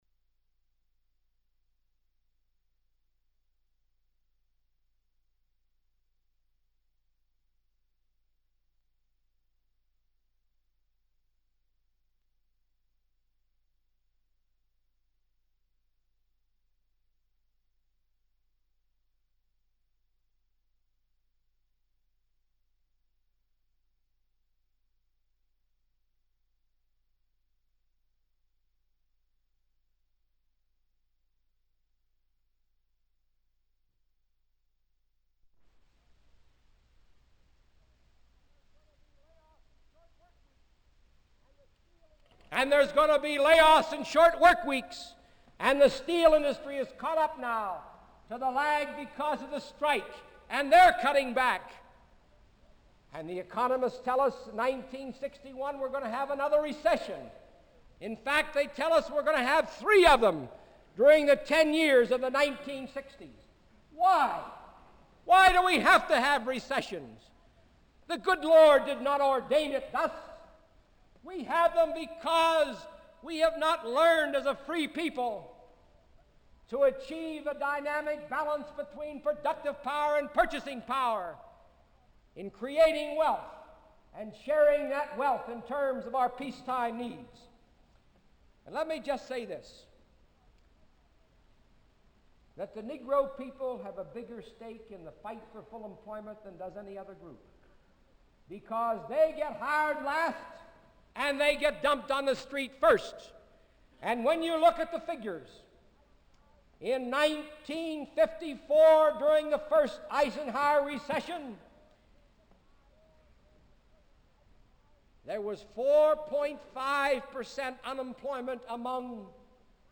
Walter P. Reuther Digital Archive · Walter P. Reuther - Ohio Civil Rights Conference, Part Two; Walter P. Reuther - Press Conference · Omeka S Multi-Repository